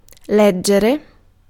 Ääntäminen
IPA : /ɹiːd/ IPA : /ɹɛd/